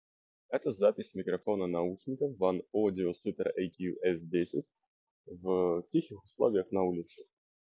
Микрофон:
Внутри каждого наушника по 2 микрофона + с помощью ИИ алгоритма идет обработка сигнала для улучшения итогового качества.
В тихих условиях: